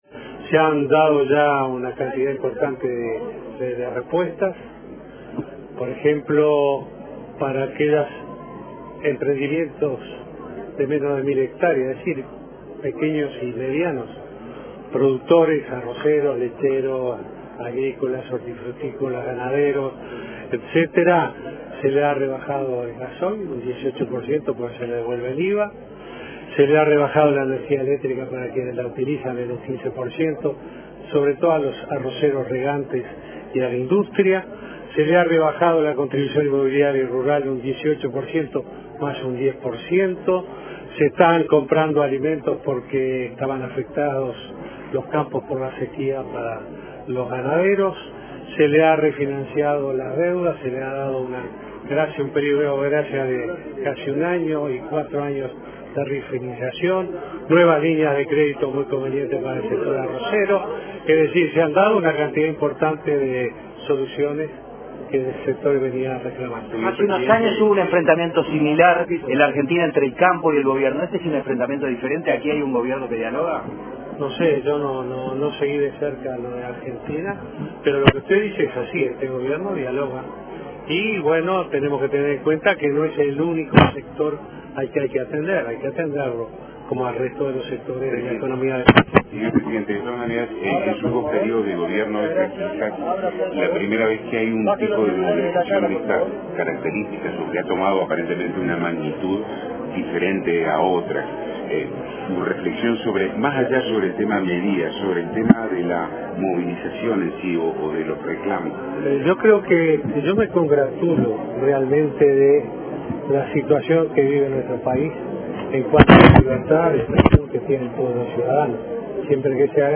El presidente Vázquez detalló a la prensa, previo al Consejo de Ministros, las medidas diseñadas por el Gobierno para paliar dificultades que atraviesa parte del sector agropecuario, como la rebaja en el precio del gasoil y la energía, la compra de alimentos, refinanciamiento de deudas, nuevas líneas de crédito y reducción de tributos.